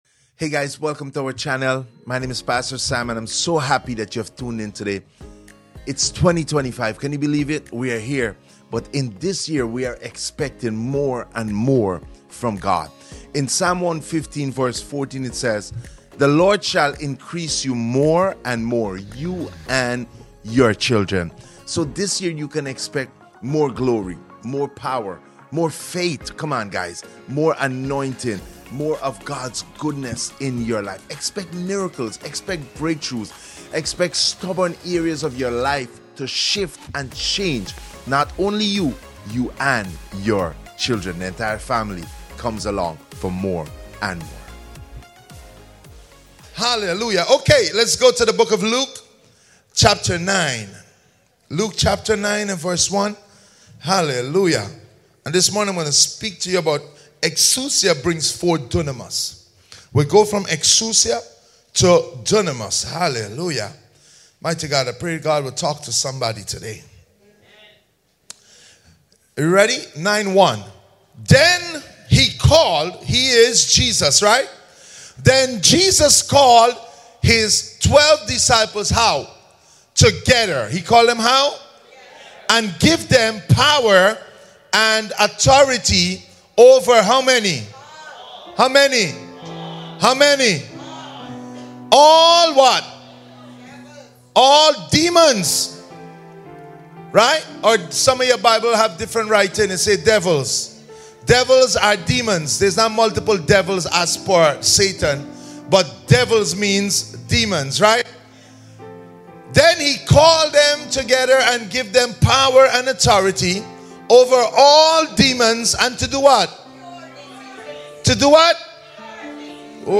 Sermons | Faith Church